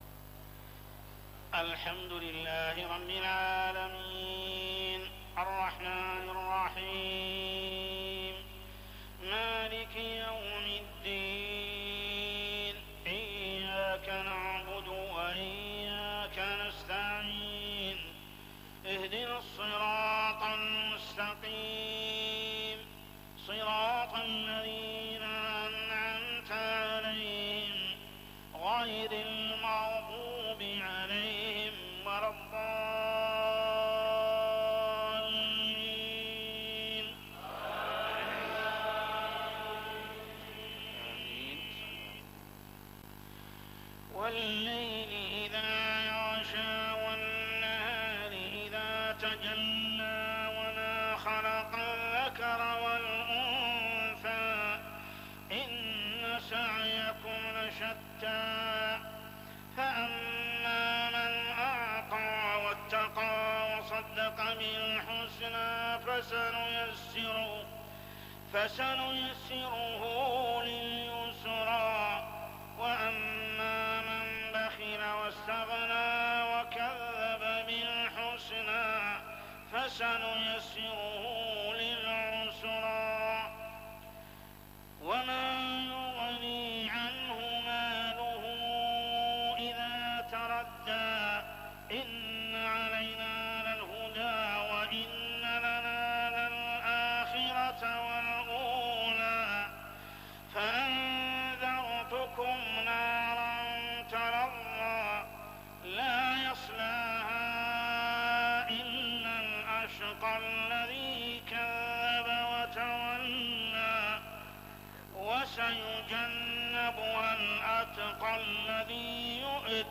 صلاة العشاء 9-9-1416هـ سورتي الليل و الضحى كاملة | Isha prayer Surah Al-Layl and Ad-Duha > 1416 🕋 > الفروض - تلاوات الحرمين